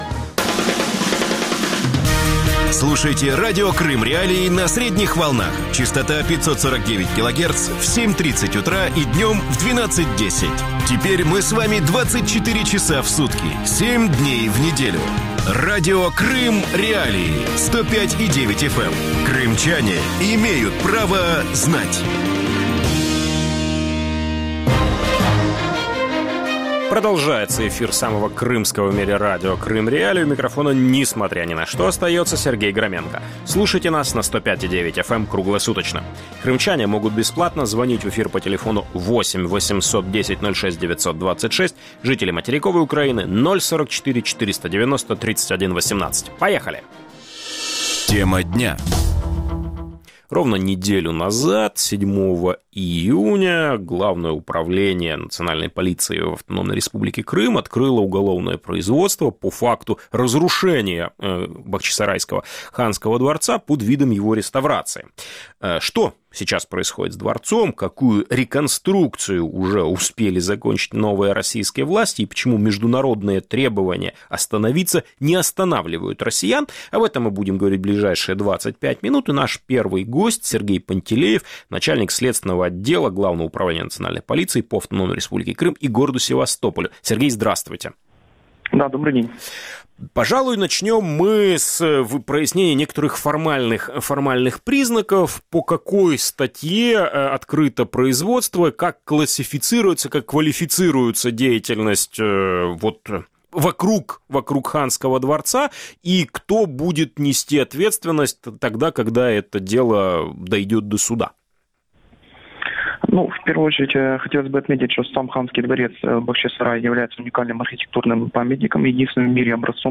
Embed На грани потери истории: «реконструкция» Ханского дворца | Радио Крым.Реалии Embed The code has been copied to your clipboard.